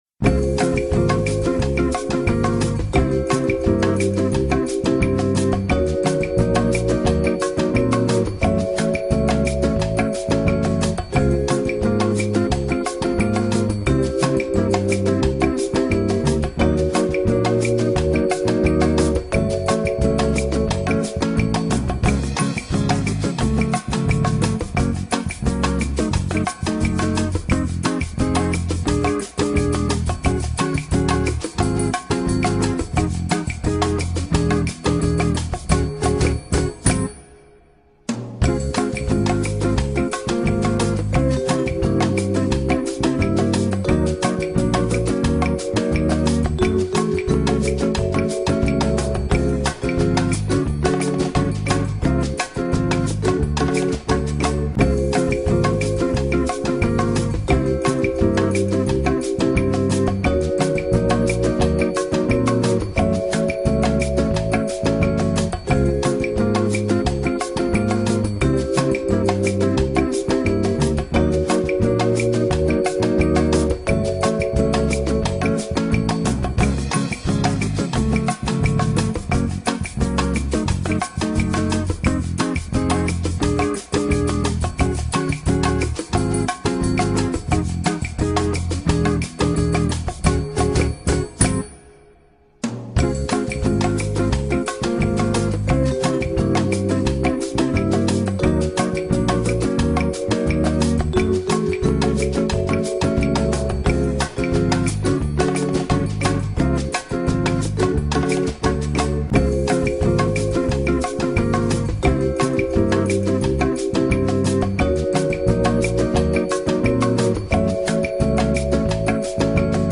موسیقی
جلوه های صوتی